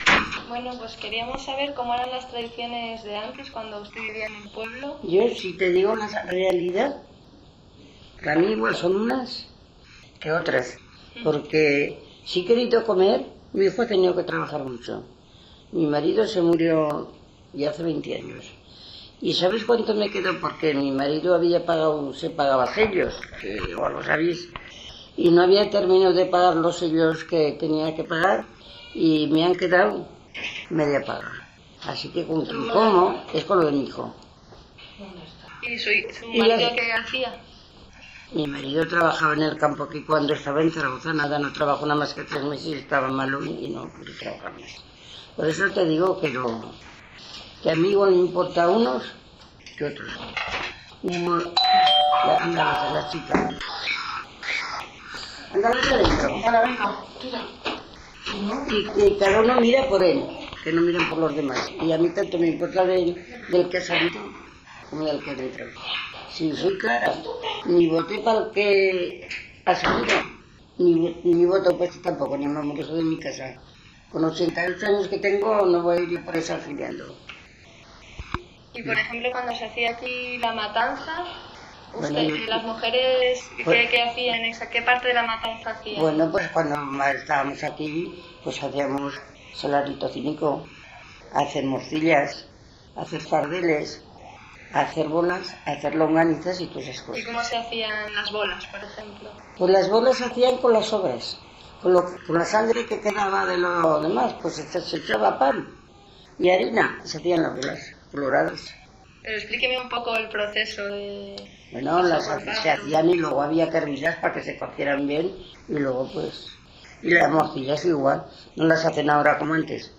Locality Cubel
I1:�mujer
I2:�mujer